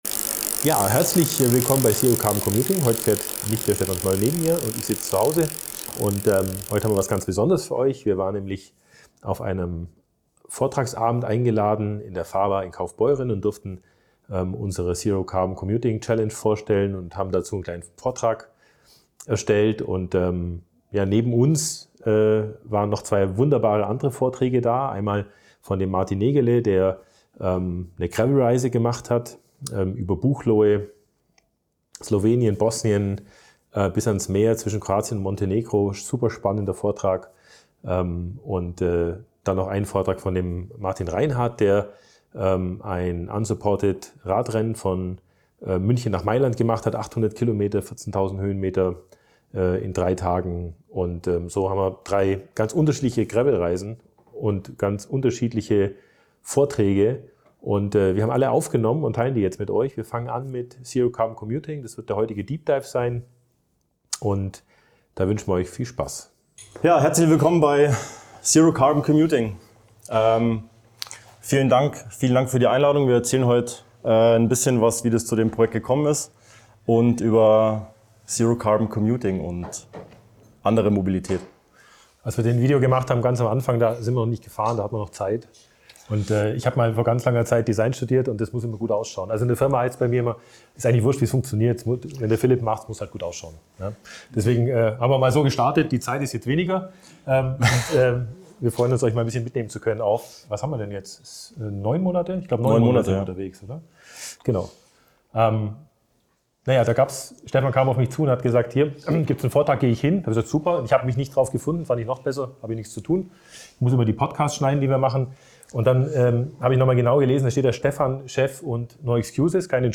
#37 - Deep Dive - Fahrbar Gravel-Abend: Vortrag ZERO CARBON COMMUTING ~ ZERO CARBON COMMUTING - Ein Jahr mit dem Fahrrad zur Arbeit (Zero Carbon Commuting) Podcast